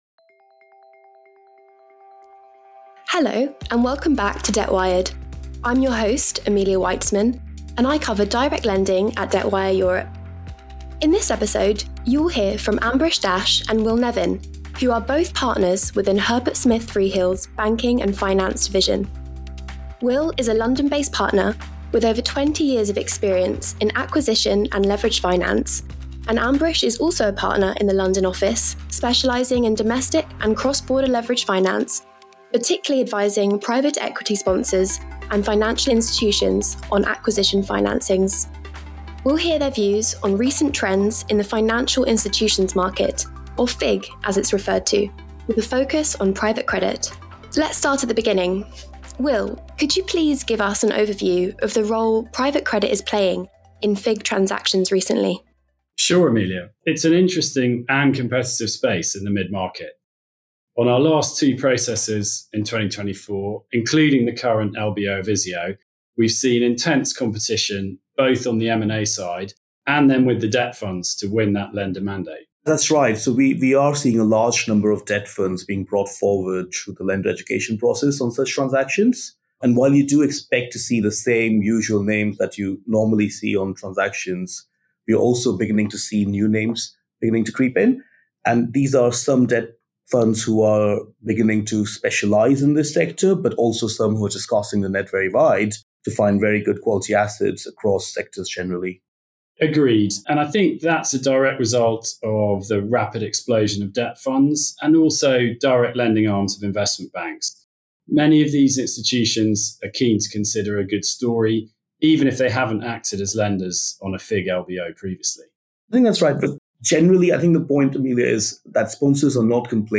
- Private Credit FIG Trends- Q&A with Herbert Smith Freehills' Finance Partners